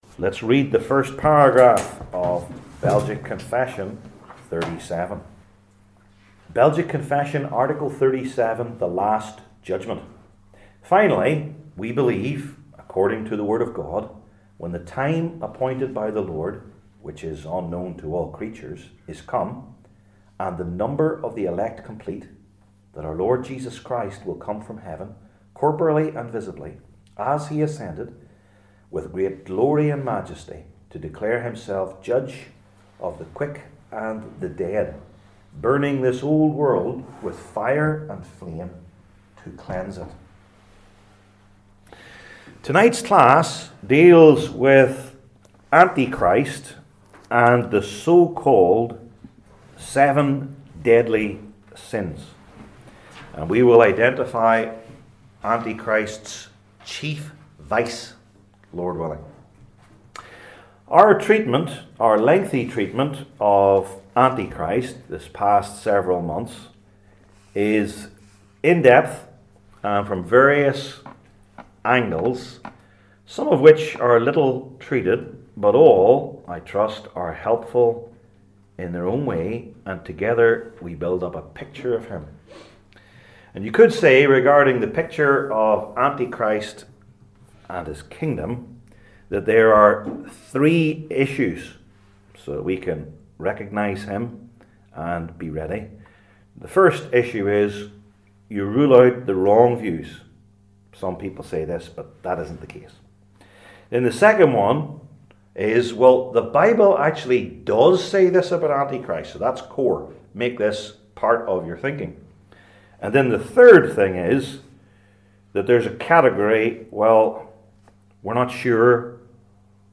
Series: Belgic Confession 37 , The Last Judgment Passage: Daniel 7:7-26 Service Type: Belgic Confession Classes